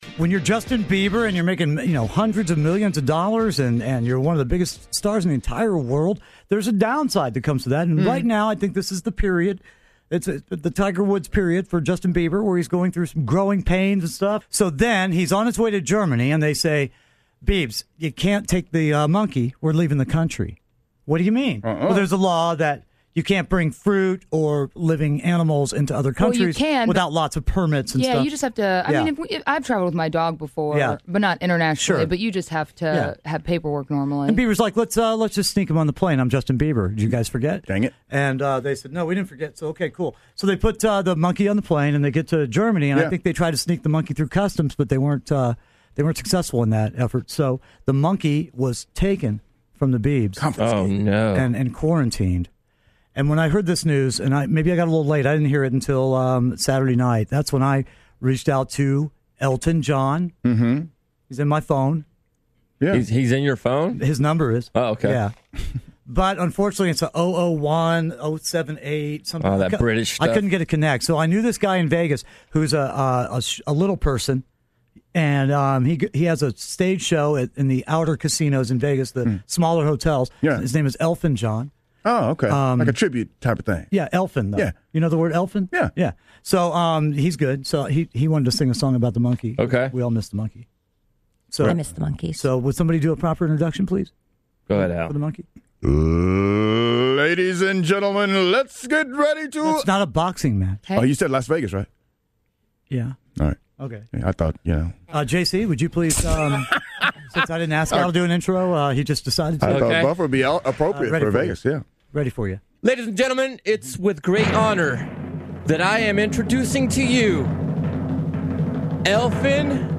Justin Bieber's pet monkey, Mally Mall, was recently quarantined in when the pop star landed in Germany. After hearing this terrible news, Kidd contacted an Elton John impersonator to sing about Justin and his monkey.